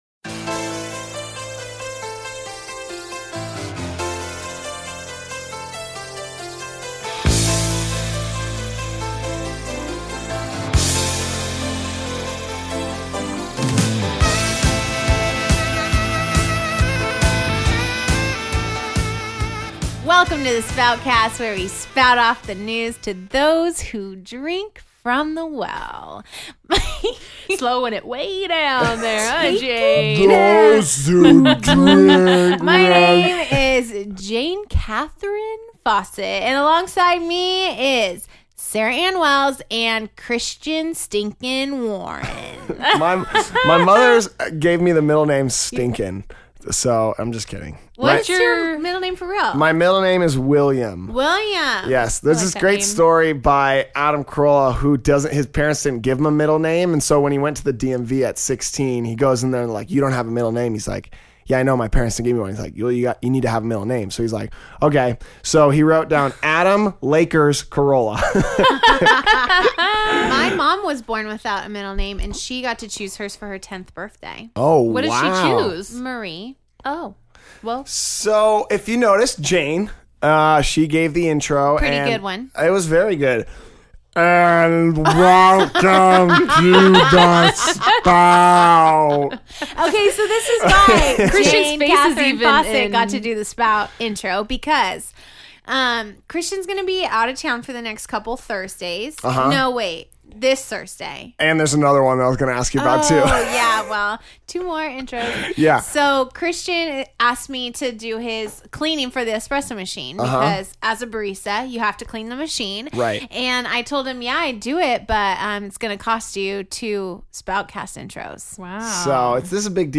The Spoutcast Crew also discusses their thoughts on the sermon and how each of them responded to the message.